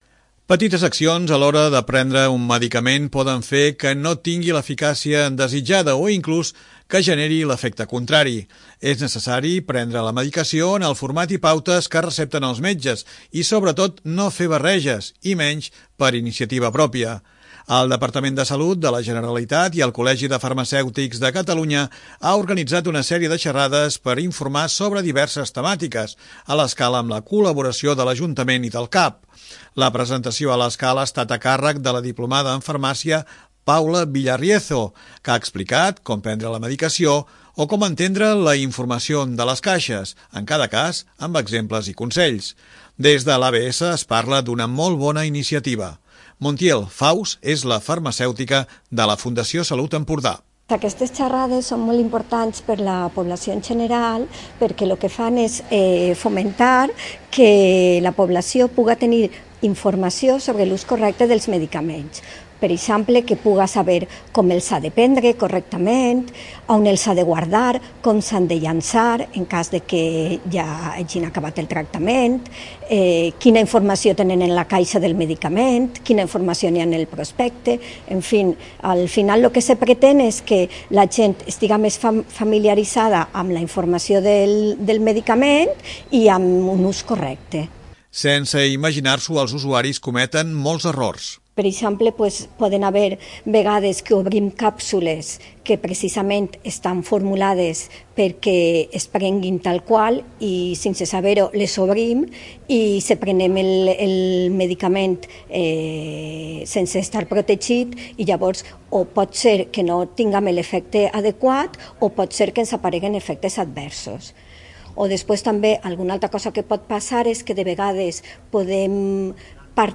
2. L'Informatiu